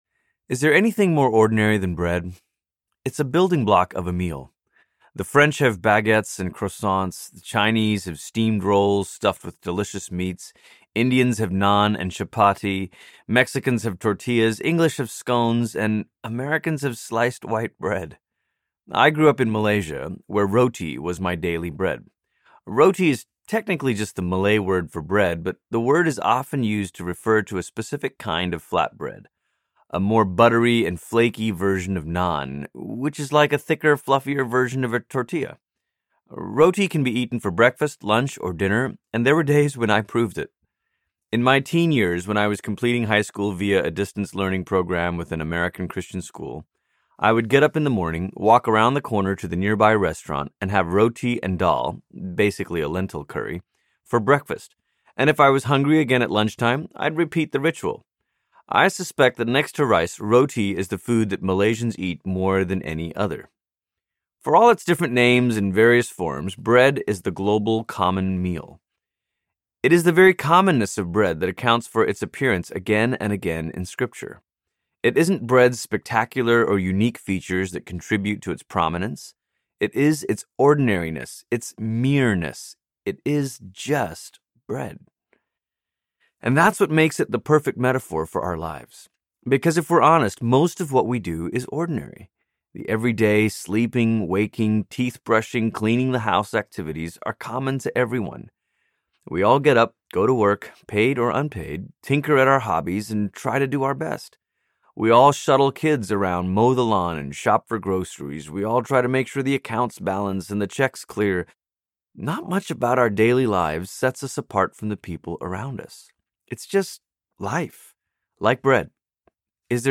Blessed Broken Given Audiobook
5.3 Hrs. – Unabridged